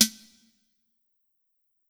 Waka SNARE ROLL PATTERN (77).wav